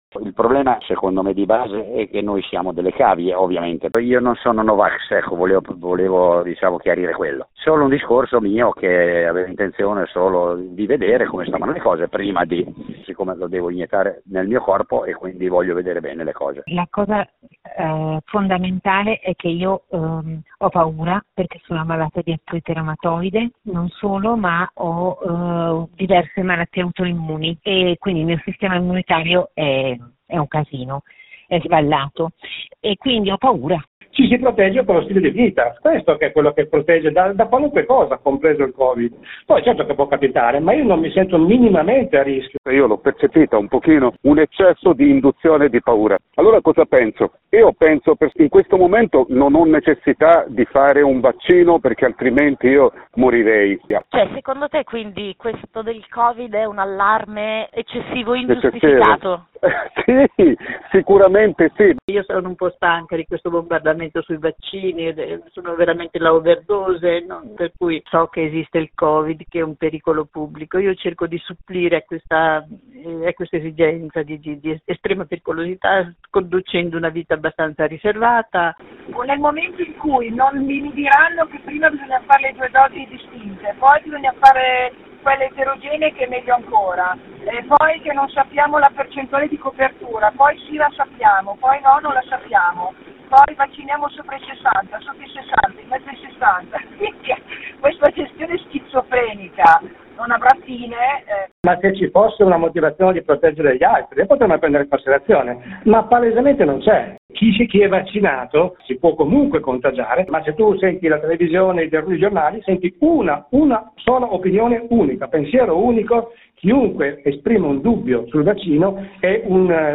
Chi sono, e perché non si vaccinano? L’inchiesta